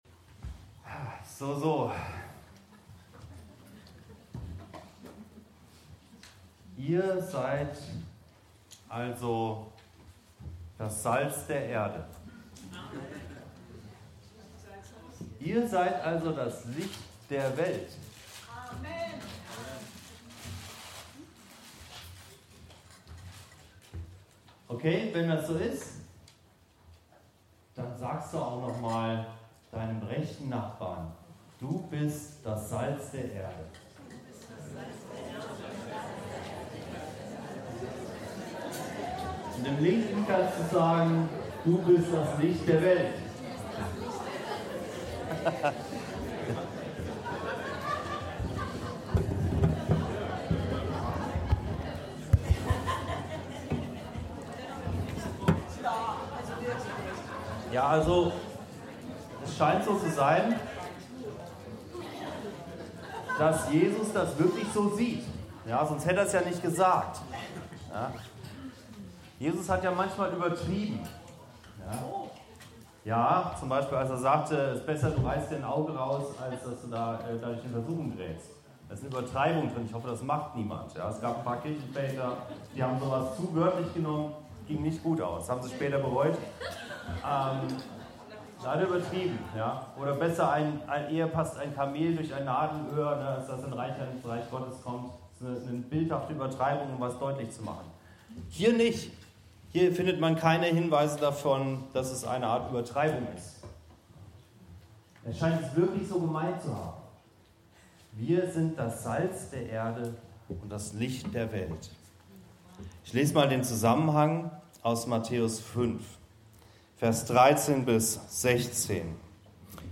Salz und Licht- das Thema unserer Gemeindefreizeit 2023.
Predigten und Lehre aus der Anskar-Kirche Hamburg-Mitte